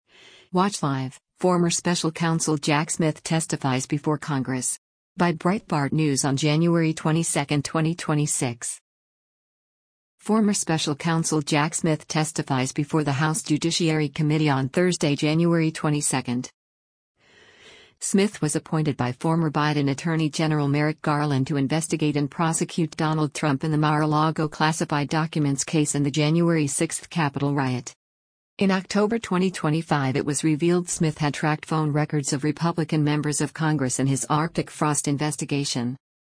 Former special counsel Jack Smith testifies before the House Judiciary Committee on Thursday, January 22.